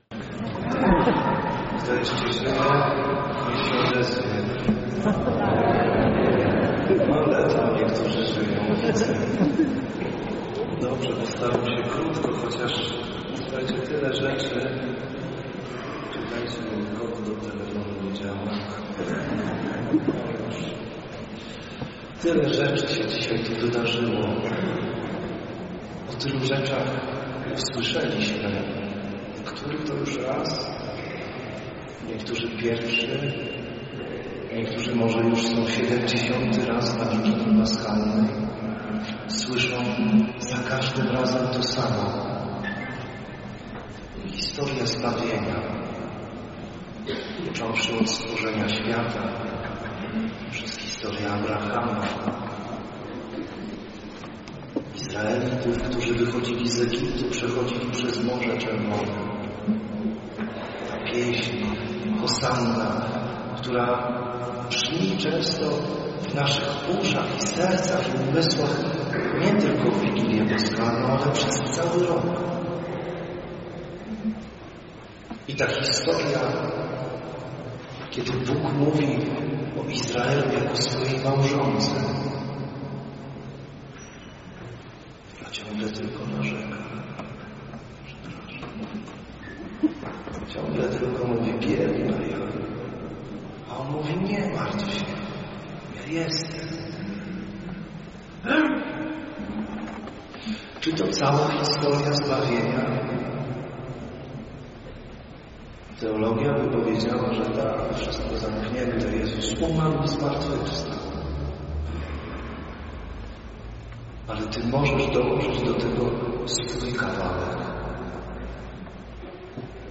Zmartwychwstanie Homilia z Wigilii Paschalnej 2024-03-30